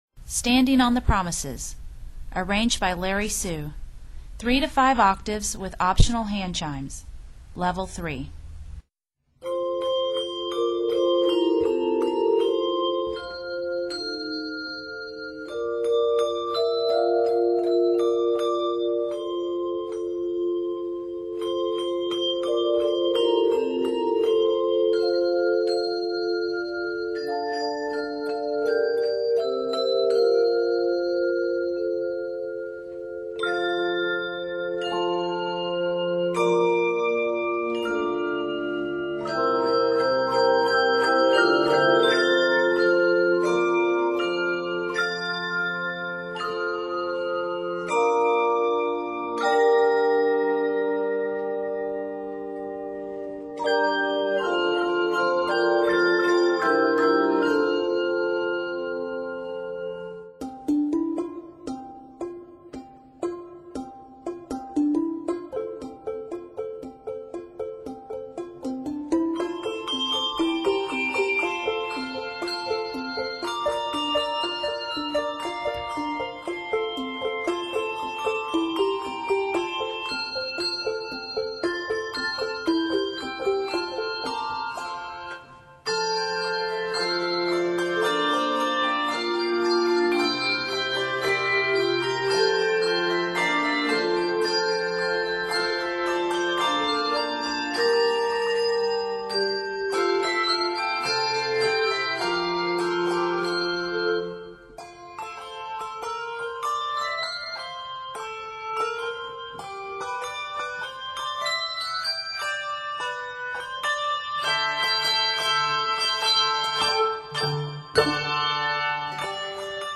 Three octaves of optional handchimes lend color
This piece is arranged in F Major.